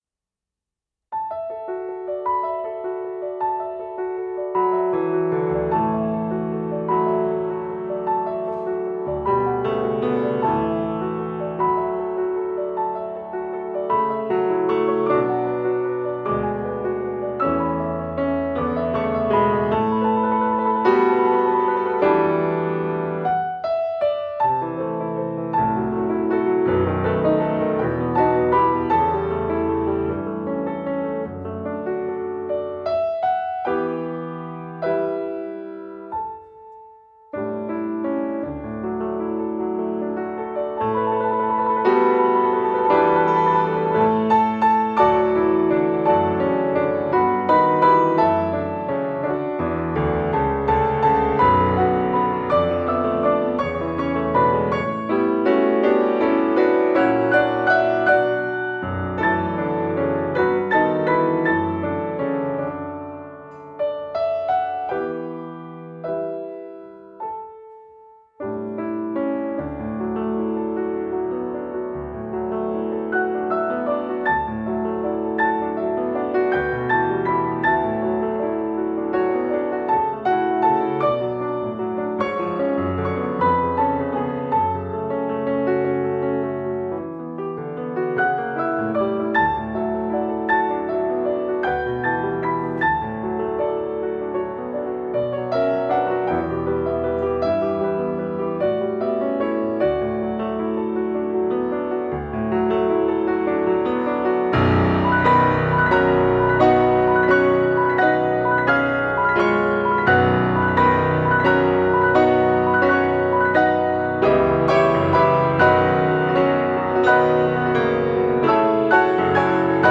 original flute and piano arrangements of familiar hymns